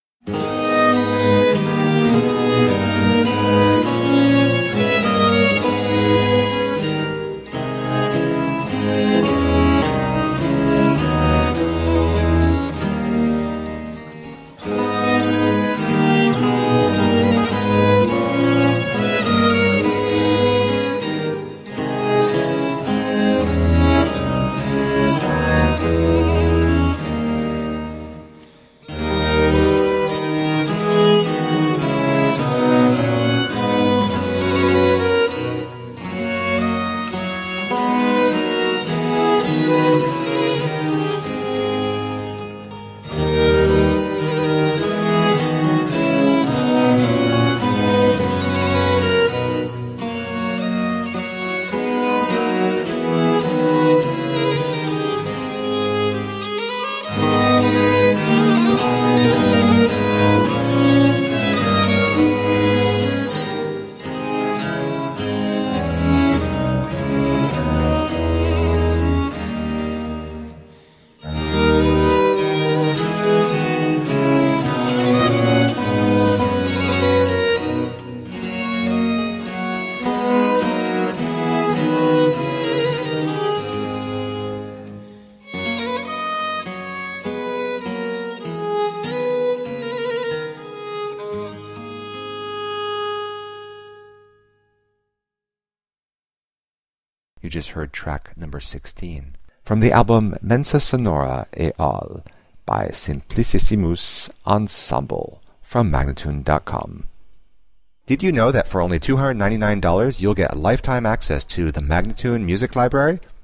17th and 18th century classical music on period instruments
Classical, Instrumental Classical, Classical Period, Baroque